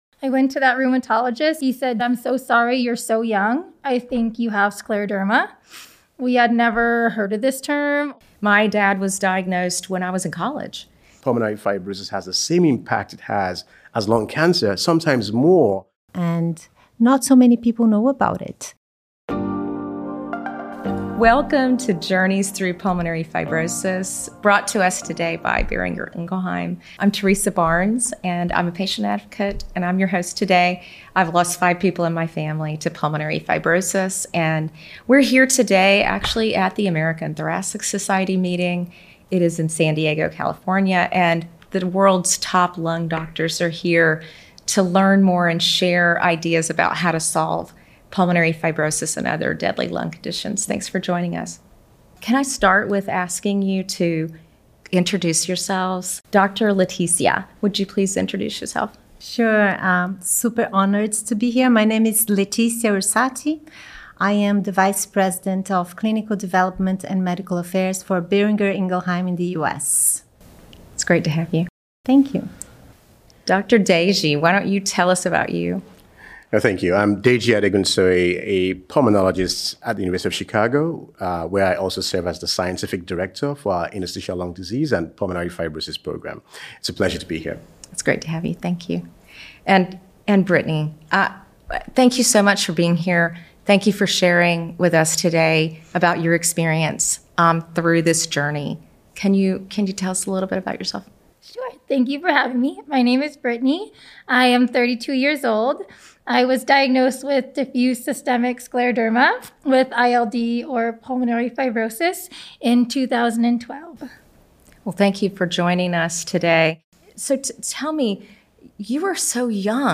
Our inspiring guests have joined together to discuss the unmet needs within pulmonary fibrosis and highlight the importance of collaboration within healthcare, and at a community level.